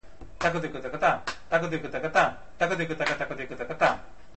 Konnakkol Script
Split Sounds
audio T' Thakku t'i'vF t'i'vF t'i'vt'i'v /F